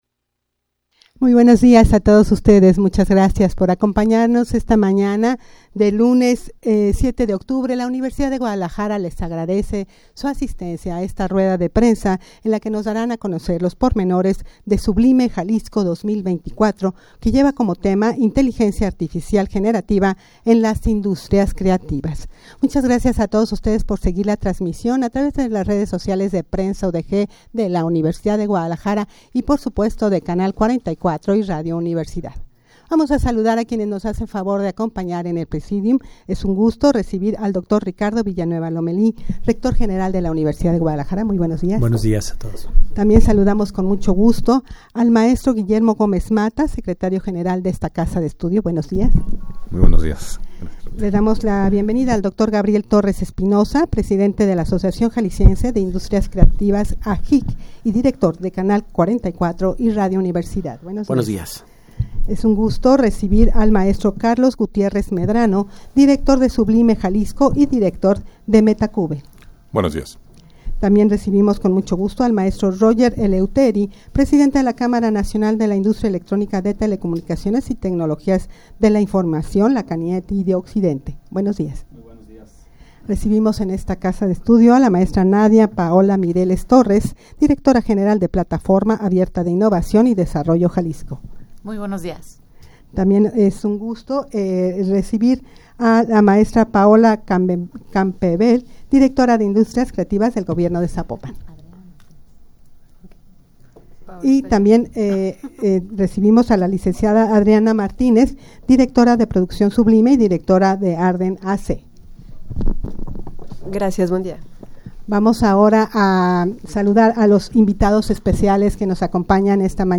Audio de la Rueda de Prensa
rueda-de-prensa-sublime-jalisco-cuyo-lema-es-inteligencia-artificial-generativa-en-las-industrias-creativas.mp3